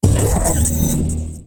attack2.ogg